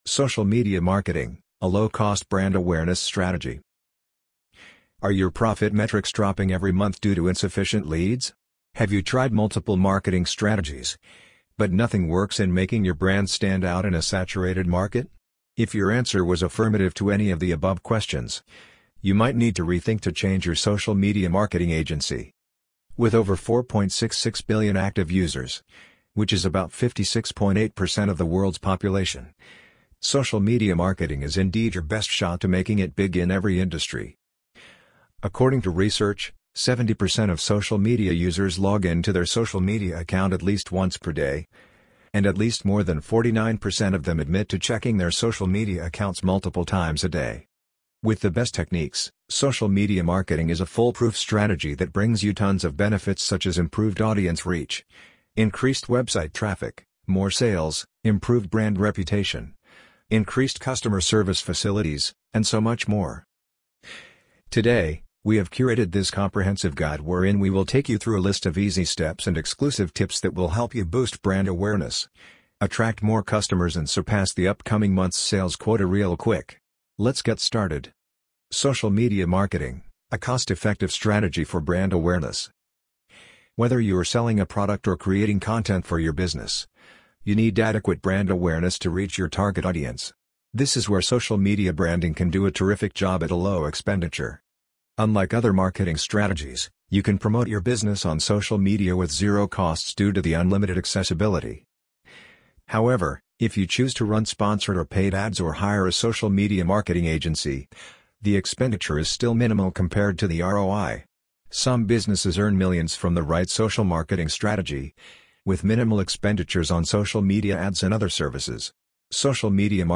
amazon_polly_7156.mp3